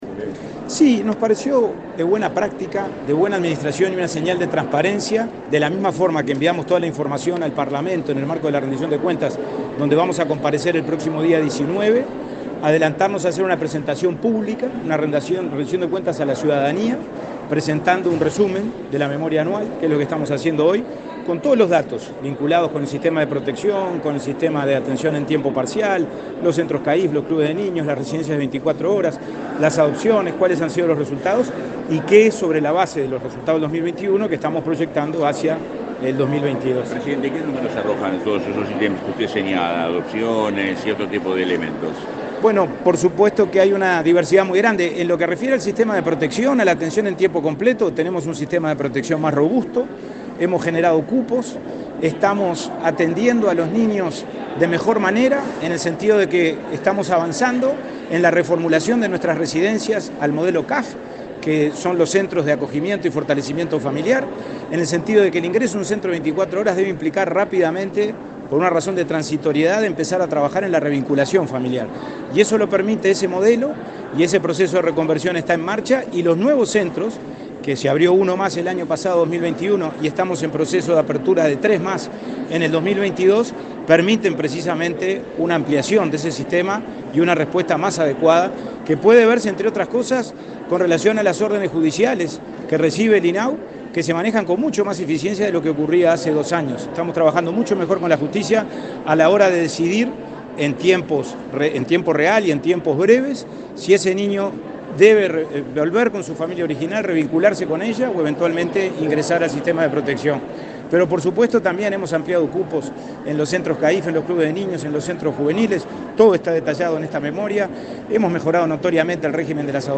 Declaraciones del presidente de INAU a la prensa
Antes, el presidente del organismo, Pablo Abdala, dialogó con la prensa.